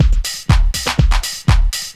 Detroit Beat 3_122.wav